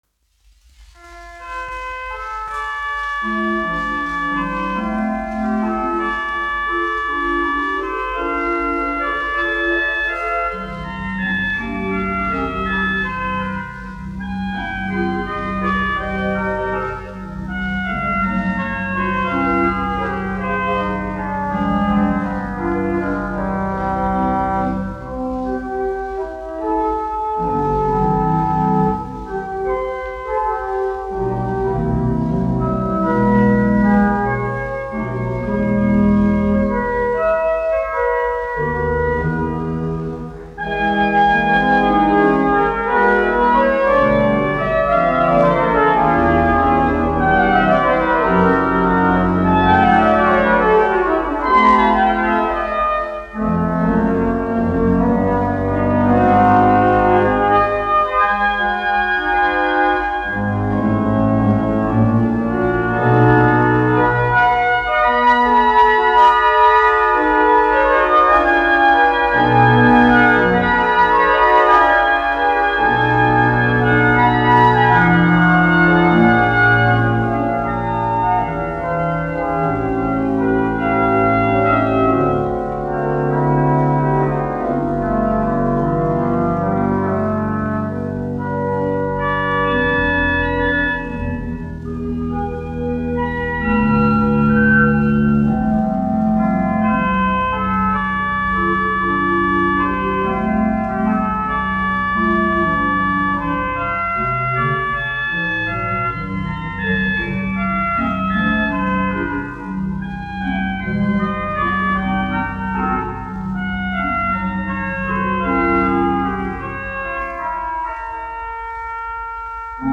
1 skpl. : analogs, 78 apgr/min, mono ; 25 cm
Ērģeļu mūzika
Latvijas vēsturiskie šellaka skaņuplašu ieraksti (Kolekcija)